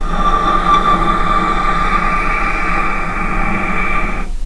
vc-D6-pp.AIF